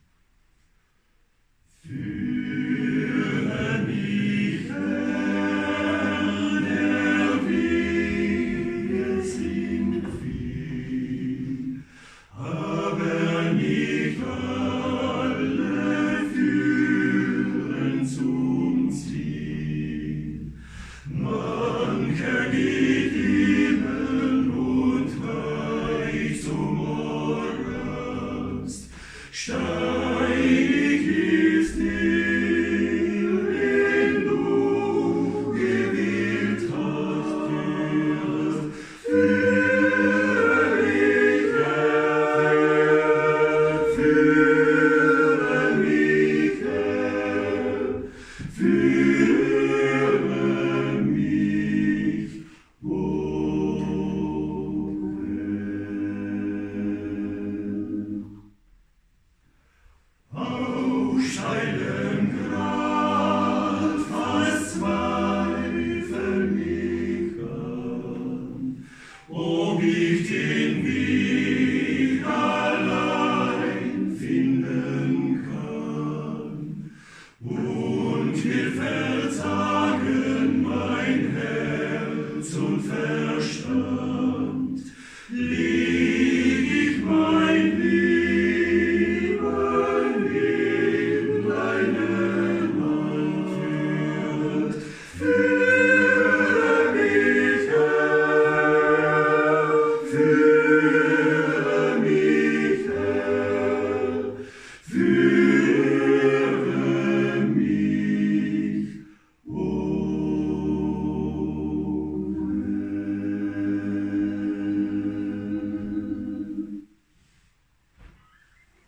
lied-8-gsang.wav